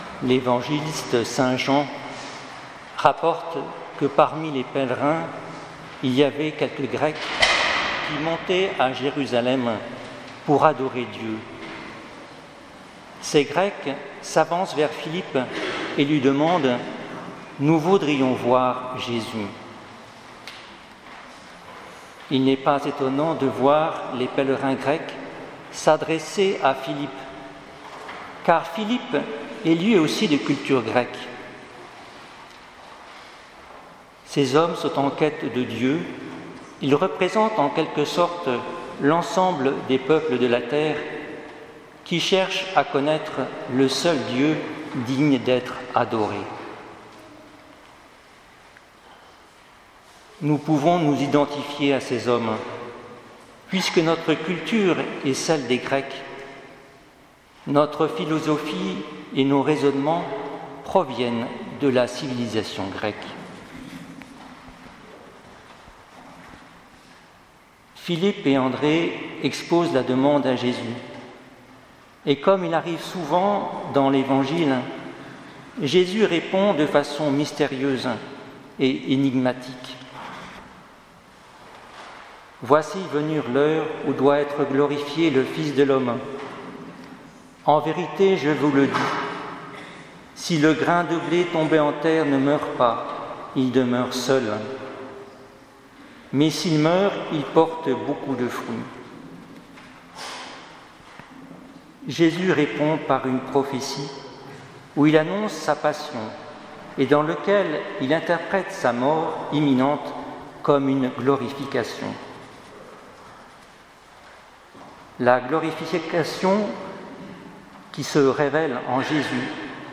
Homélie du 5ème dimanche de Carême